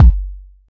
Kick (1).wav